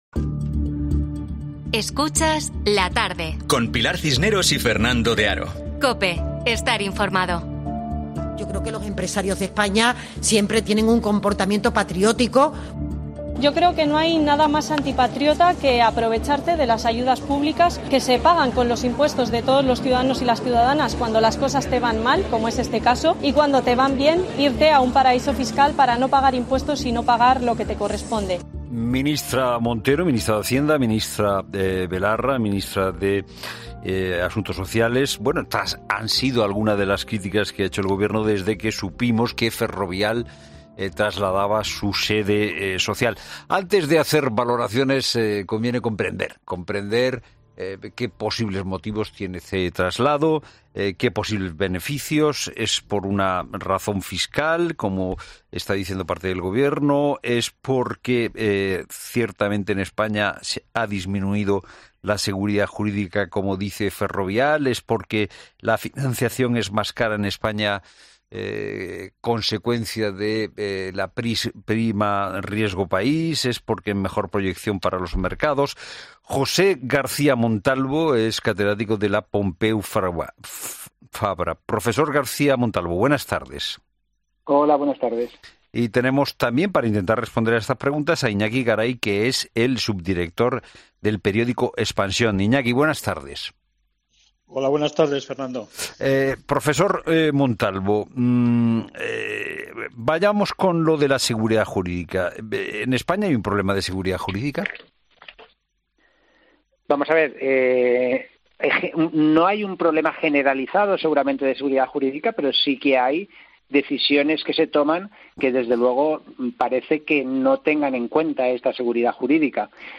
'La Tarde' analiza con expertos la decisión de multinacional de cambiar su sede y qué consecuencias conlleva